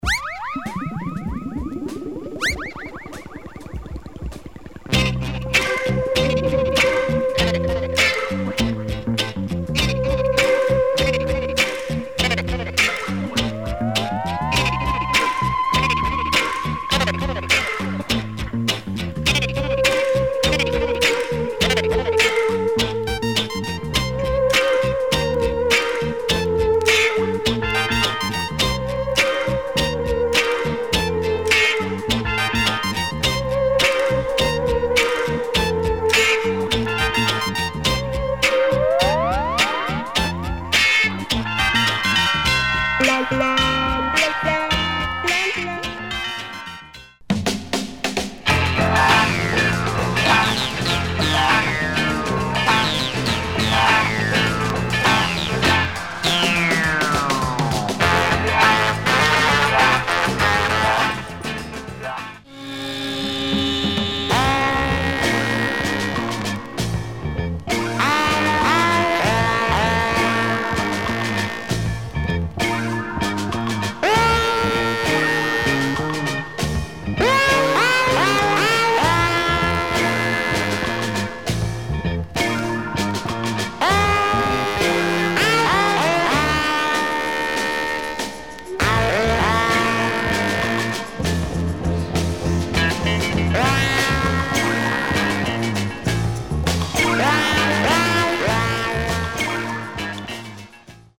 The finest electronic pop jazz
That bass line ! Those effects !